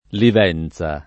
[ liv $ n Z a ]